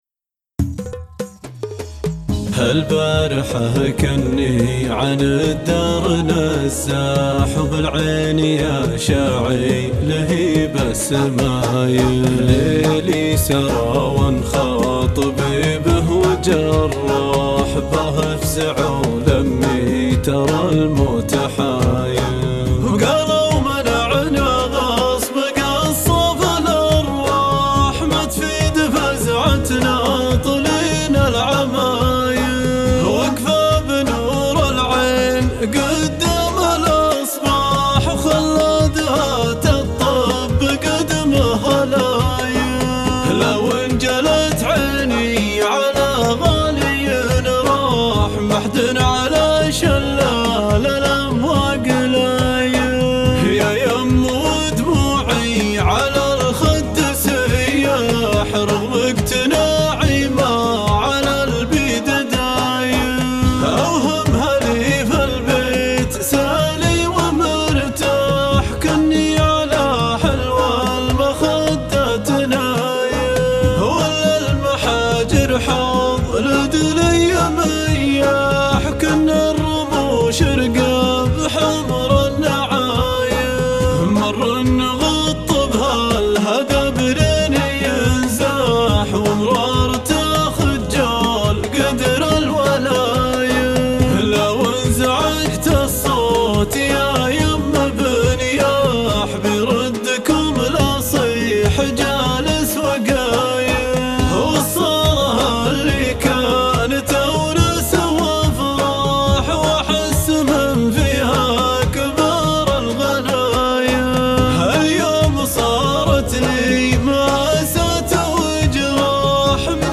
ايقاع